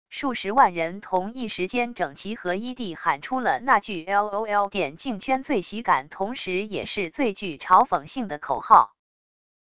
数十万人同一时间整齐合一地喊出了那句lol电竞圈最喜感同时也是最具嘲讽性的口号wav音频